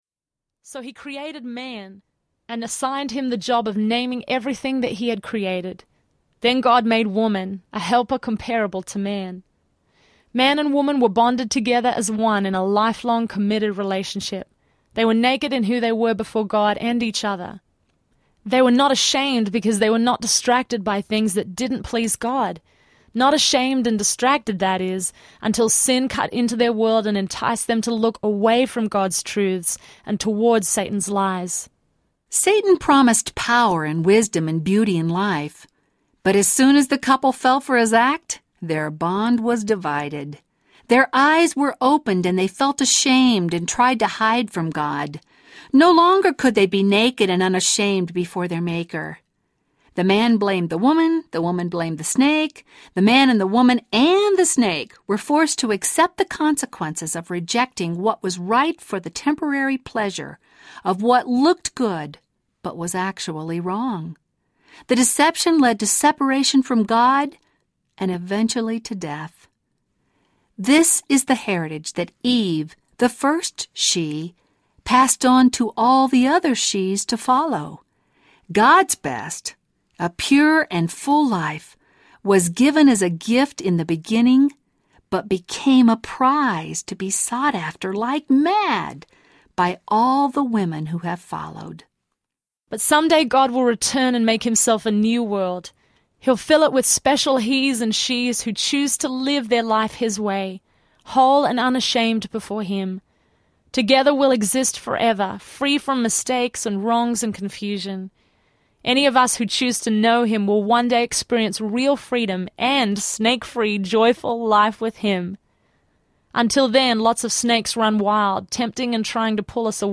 SHE Audiobook
6.5 Hrs. – Unabridged